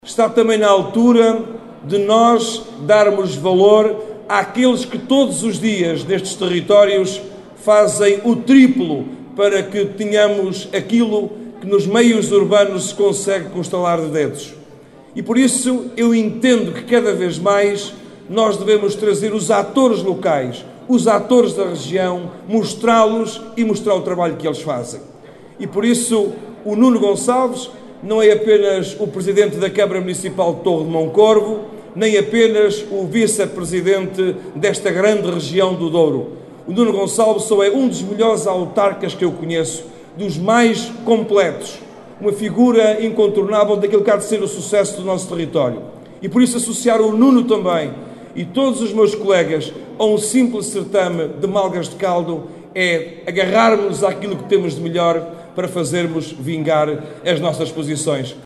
O autarca diz que, chegou a altura de o Governo “dar valor aos concelhos que lutam todos os dias para se afirmarem num país que continuam a duas velocidades”.
Carlos Santiago diz que, “os concelhos do interior querem continuar a fazer parte do futuro de Portugal”.